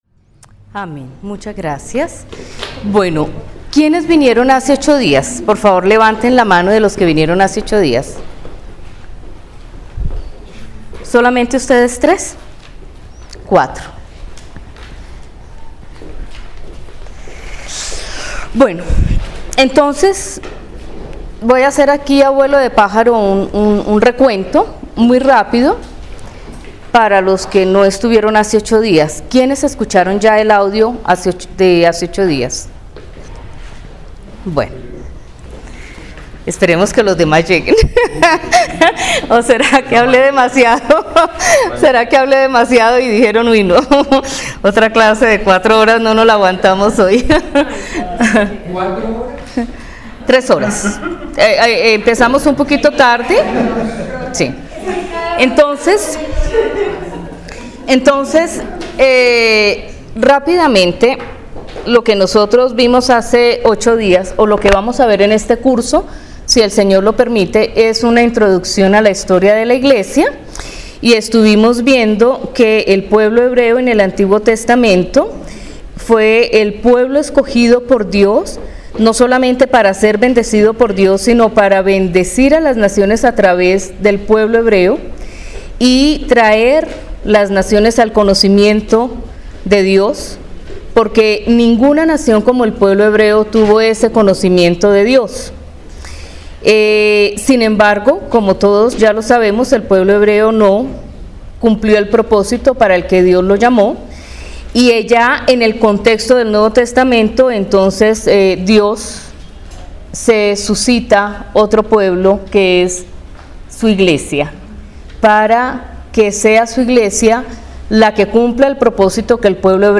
Lección 1: Introducción a la historia del cristianismo III (Marzo 10, 2018)